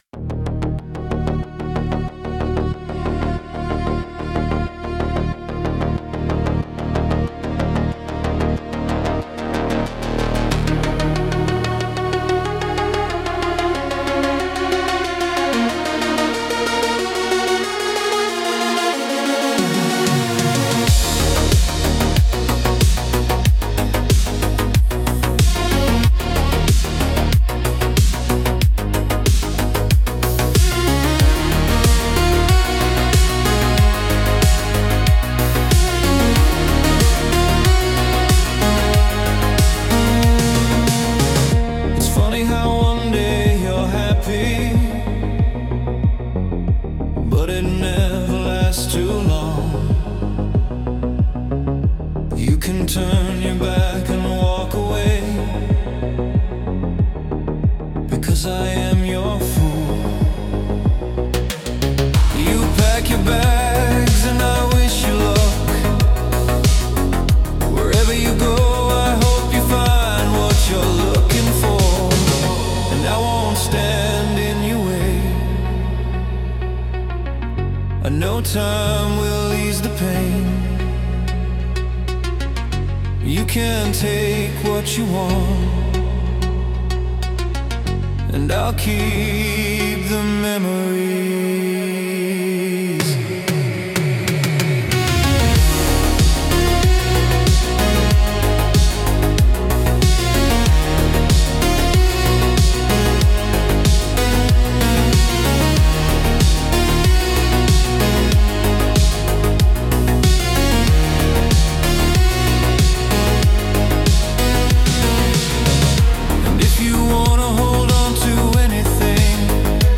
• Synthpop / Indie Synthpop
• With light dream-pop and retro-pop undertones
• Smooth, melodic 80s-inspired synth textures
• Steady electronic drum groove with a modern, clean mix
• ≈ 108–112 BPM
• Nostalgic but current